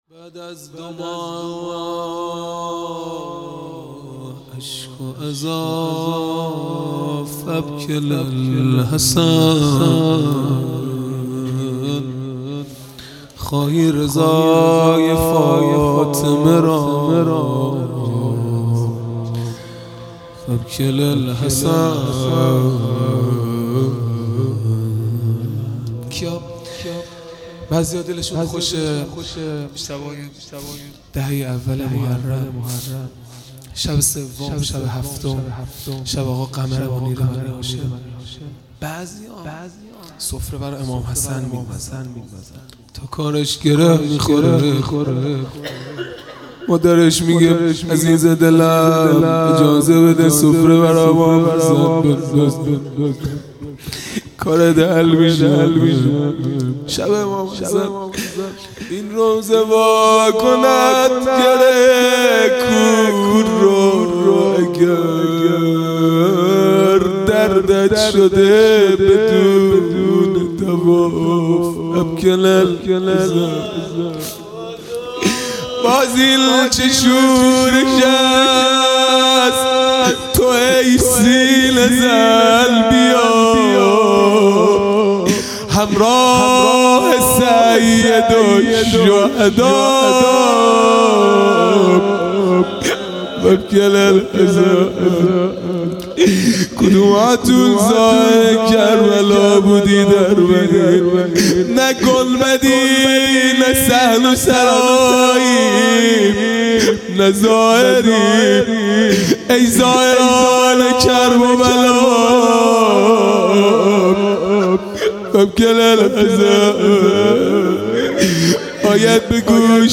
خیمه گاه - هیئت بچه های فاطمه (س) - مناجات پایانی | بعد از دو ماه اشک و عزا
عزاداری دهه آخر صفر المظفر (شب دوم)